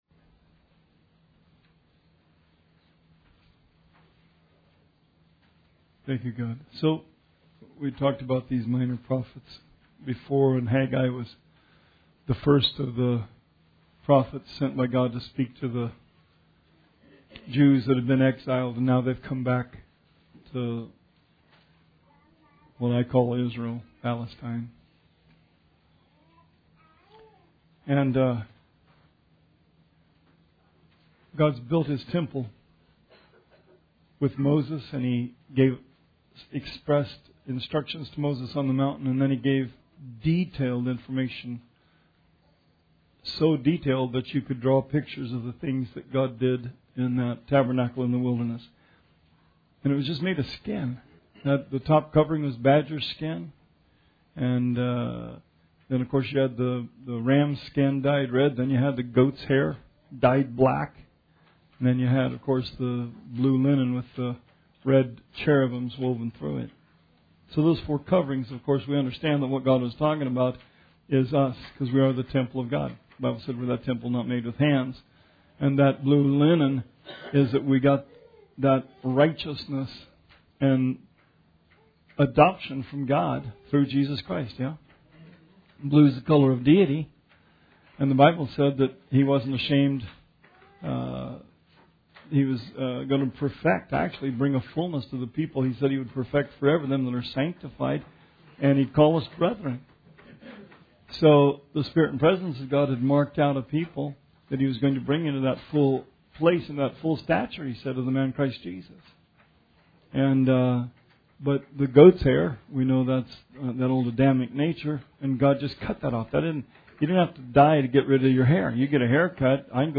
Bible Study 7/11/18